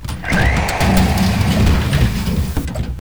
debris.wav